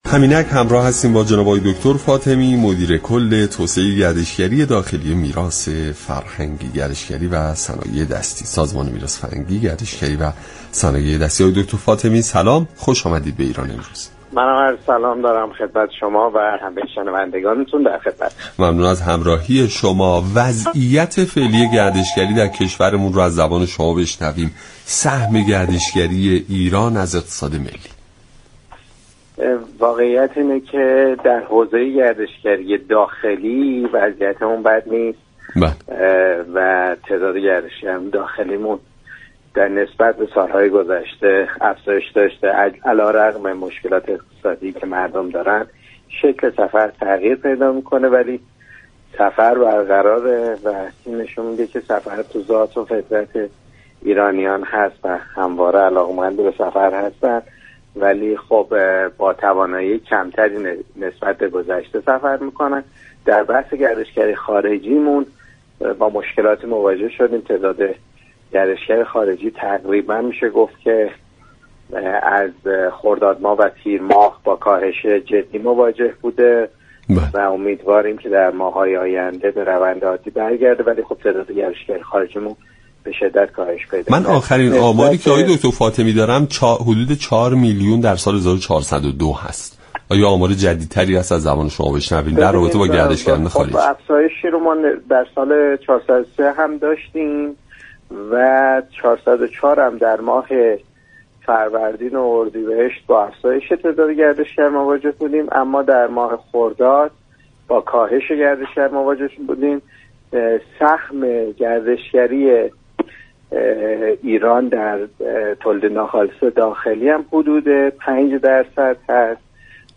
مدیر كل توسعه گردشگری داخلی وزارت میراث فرهنگی، گردشگری و صنایع دستی در ایران امروز گفت: علی‌رغم مشكلات اقتصادی سفر همچنان در بین ایرانیان برقرار است.